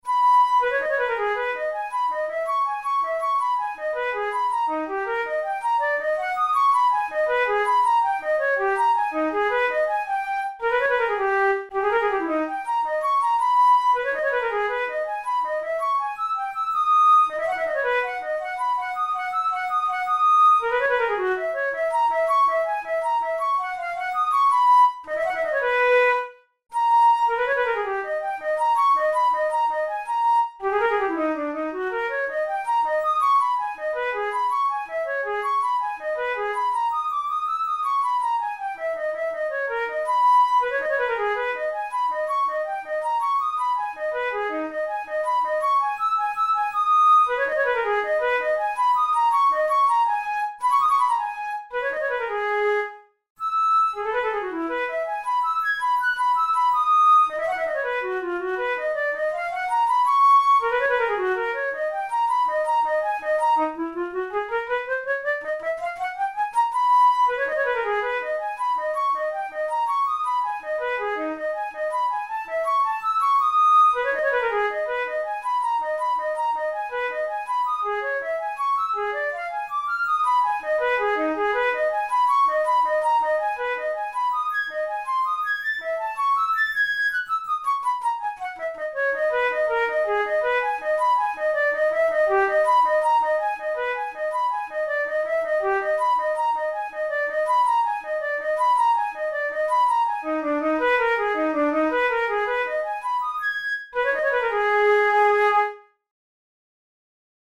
Categories: Etudes Romantic Written for Flute Difficulty: advanced